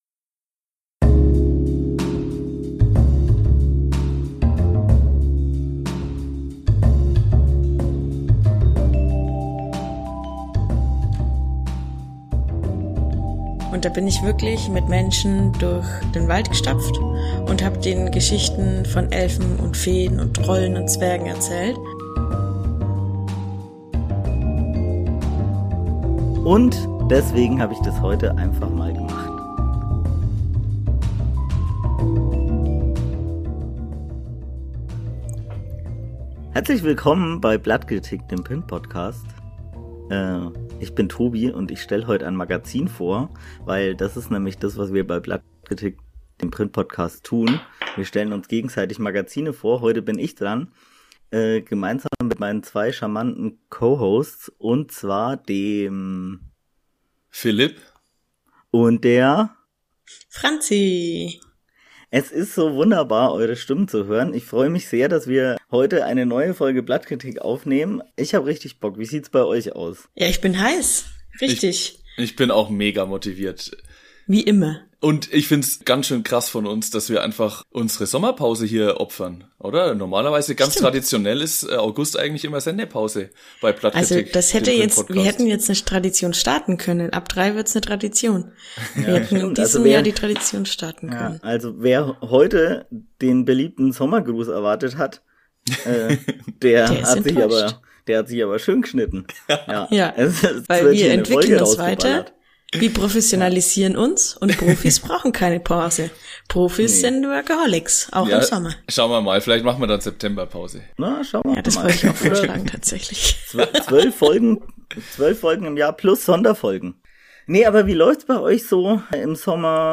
Mal wieder bisschen entspannen, am besten im eigenen Garten! Der Deutschen liebstes Hobby (Quelle: das Magazin dieser Folge) nehmen unsere drei Pod-Hosts heute genauer unter die Lupe.